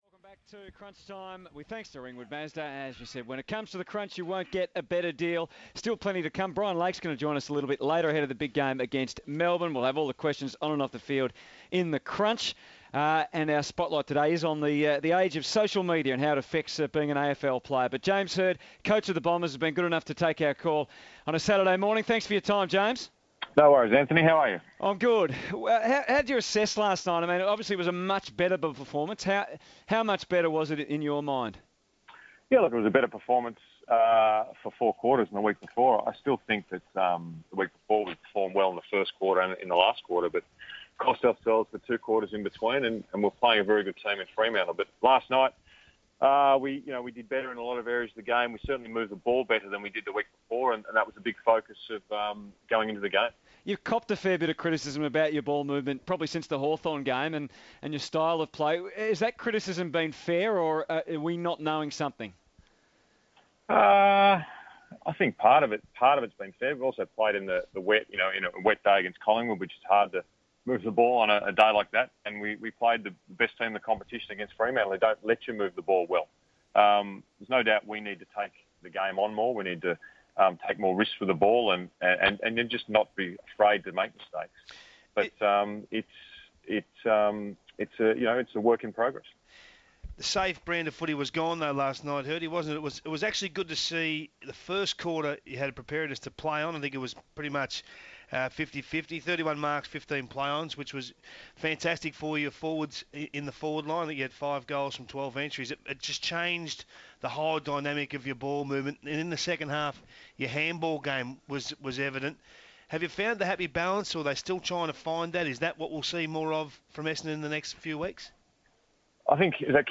Essendon coach James Hird had a fascinating chat with the boys on Crunch Time.